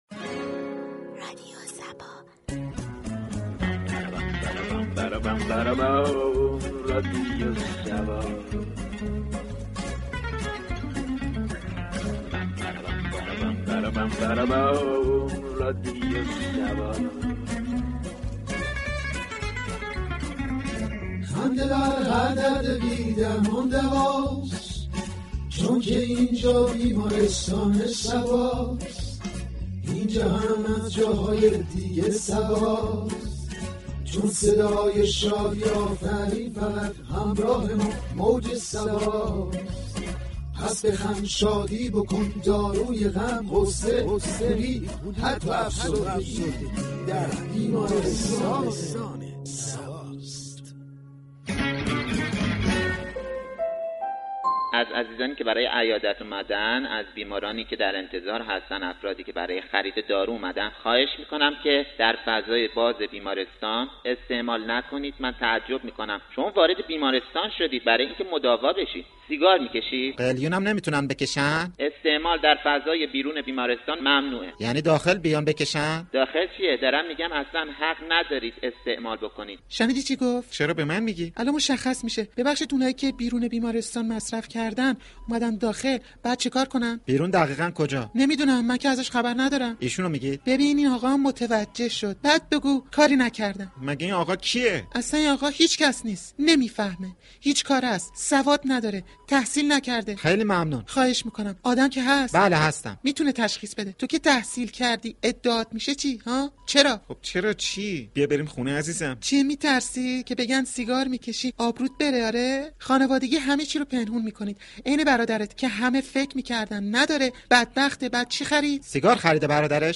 برنامه طنز «بیمارستان صبا» به دنبال ارتقاء آگاهی و فرهنگسازی در حوزه سلامت به موضوع مصرف دخانیات می پردازد.
به گزارش روابط عمومی رادیو صبا، «بیمارستان صبا» یكی از برنامه های طنز این شبكه رادیویی است، كه با نگاهی طنز به بیان مسائل فرهنگی و اجتماعی جامعه می پردازد.